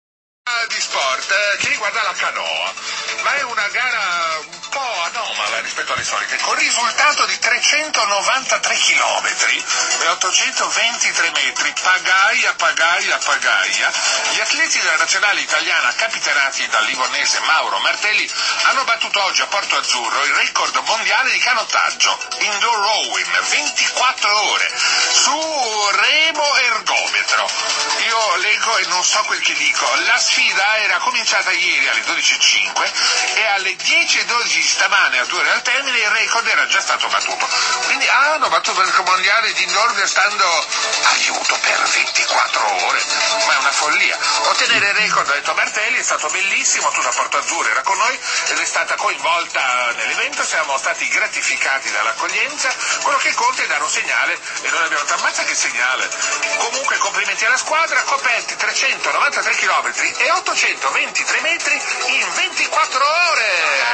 Comunicato Radio Deejay conquista Record
FILE AUDIO RECORD 24H RADIO DEEJAY_PLATINETTE 0.mp3